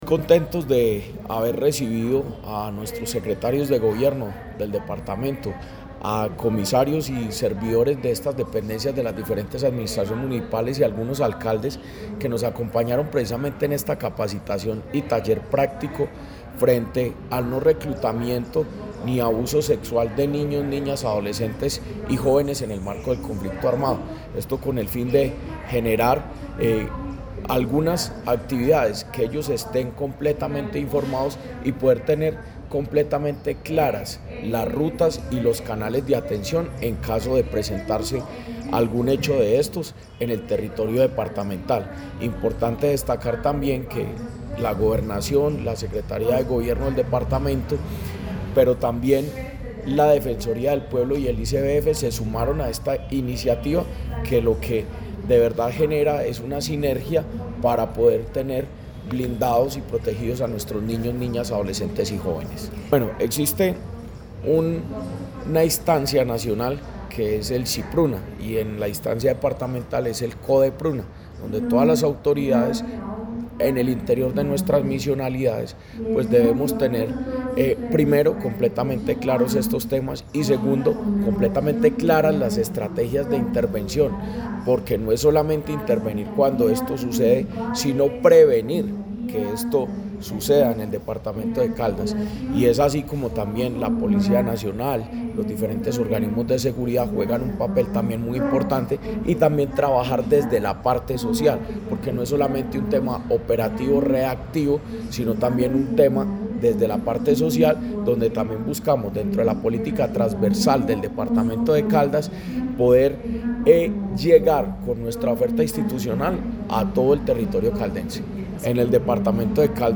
Jorge Andrés Gómez Escudero, secretario de Gobierno de Caldas.
Jorge-Andres-Gomez-Escudero-secretario-de-Gobierno-de-Caldas-Prevencion-reclutamiento.mp3